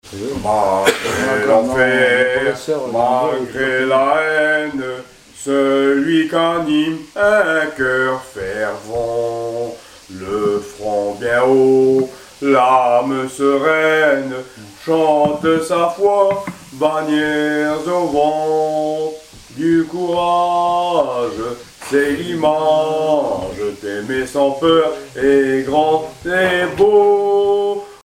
circonstance : cantique
Genre strophique
Témoignage et chansons maritimes
Pièce musicale inédite